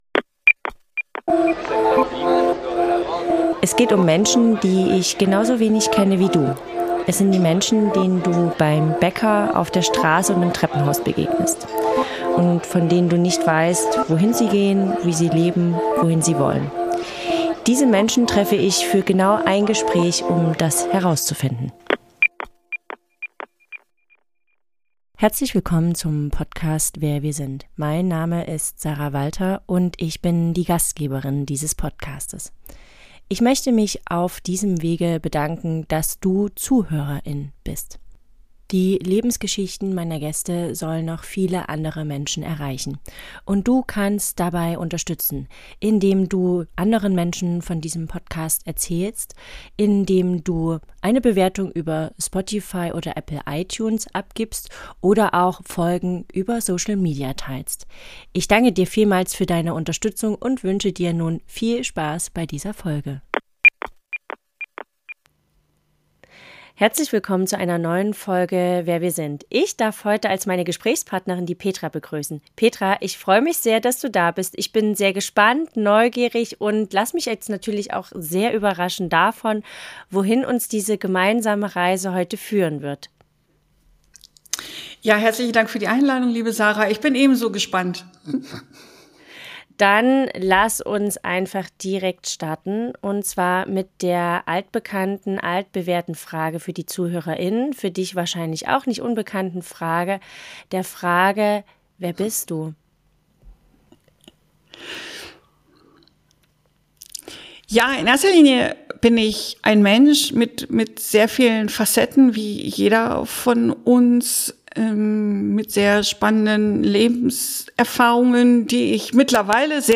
Es ist ein Gespräch über Verlust und Hoffnung, über Wandel und inneren Frieden – und darüber, wie wir mit all dem unseren Weg finden können.